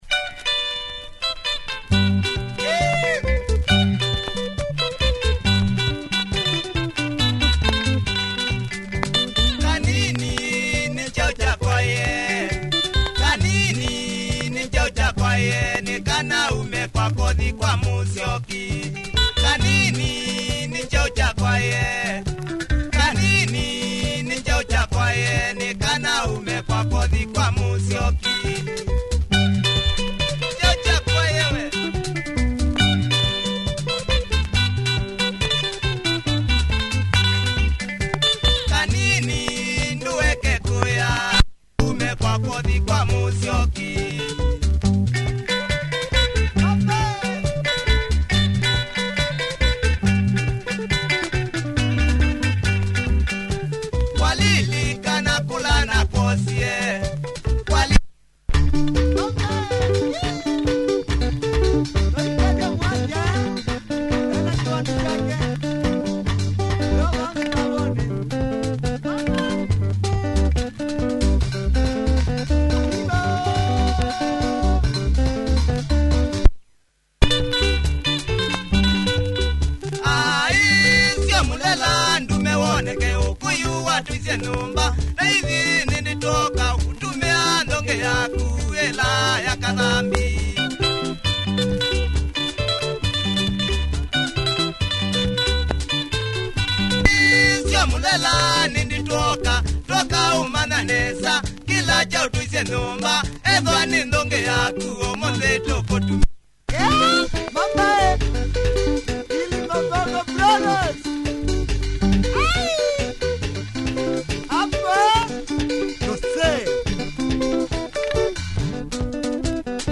Fine kamba benga here by this legendary outfit